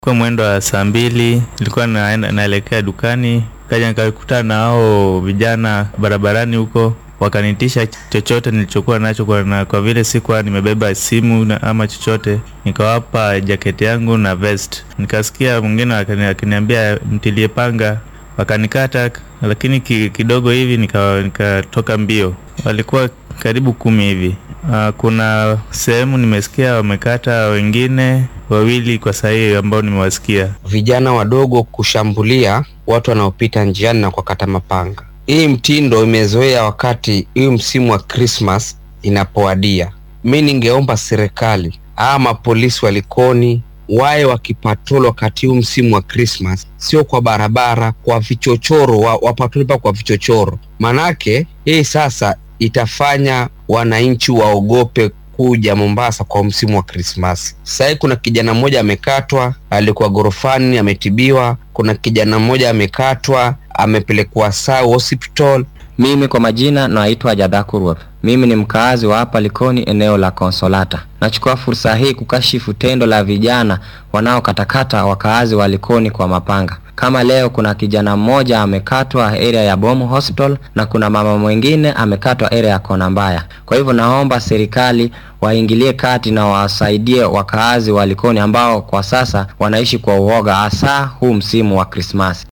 Qaar ka mid ah shacabka Likoni oo falalkan warbaahinta uga warramay ayaa dareenkooda sidan u muujiyay